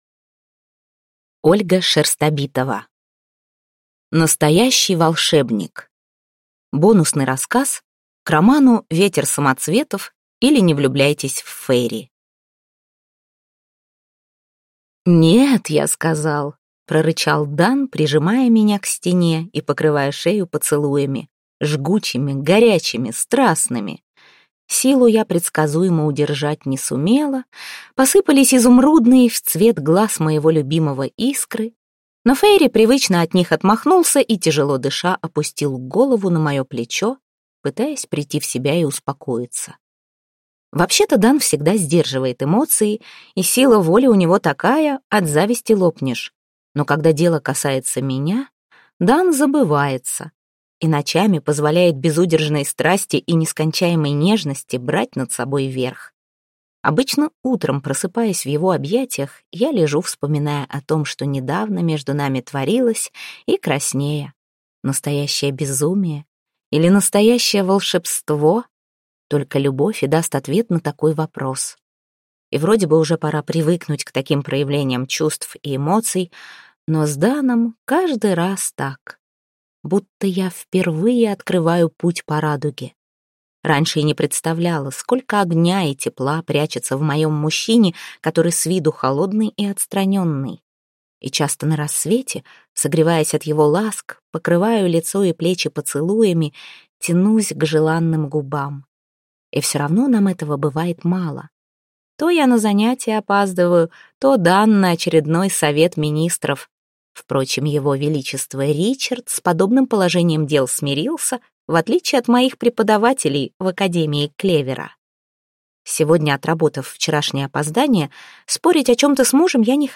Аудиокнига Настоящий волшебник | Библиотека аудиокниг